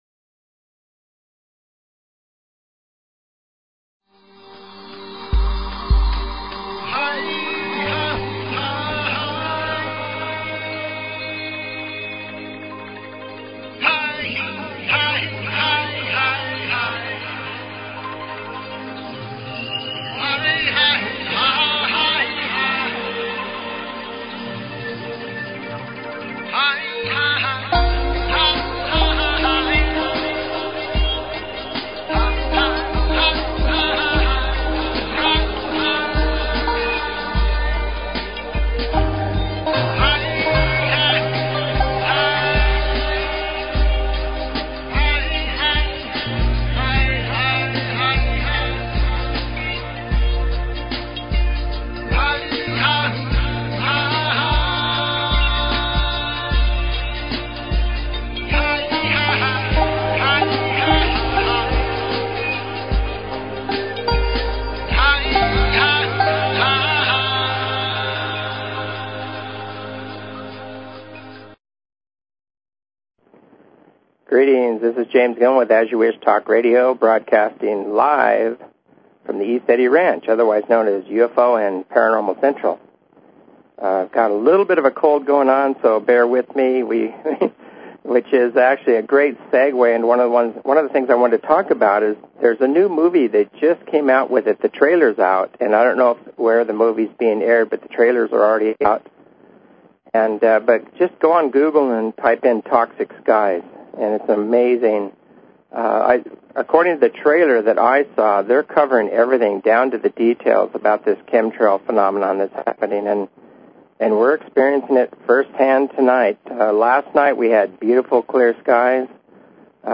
Talk Show Episode, Audio Podcast, As_You_Wish_Talk_Radio and Courtesy of BBS Radio on , show guests , about , categorized as
As you Wish Talk Radio, cutting edge authors, healers & scientists broadcasted Live from the ECETI ranch, an internationally known UFO & Paranormal hot spot.